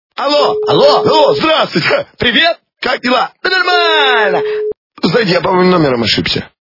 » Звуки » Смешные » Мужской голос - ... Ой, я вроде ошибся номером...
При прослушивании Мужской голос - ... Ой, я вроде ошибся номером... качество понижено и присутствуют гудки.
Звук Мужской голос - ... Ой, я вроде ошибся номером...